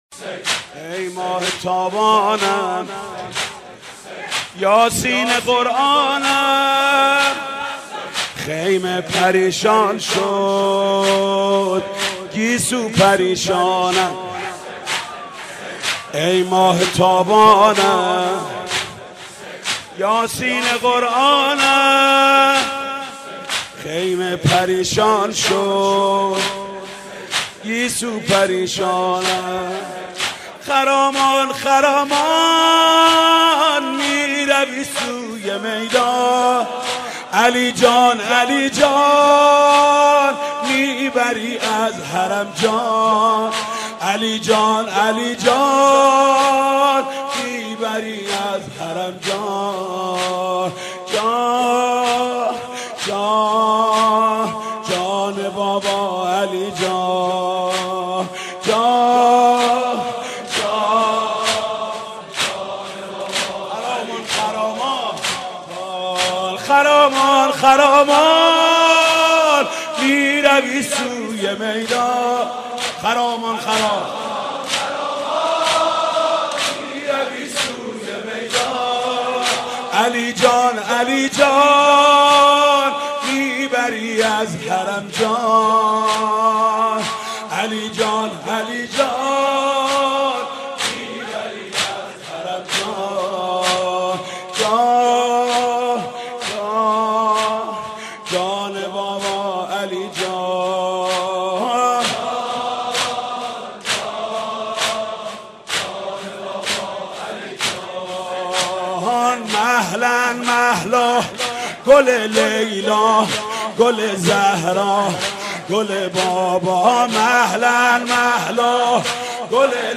عزاداری :